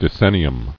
[de·cen·ni·um]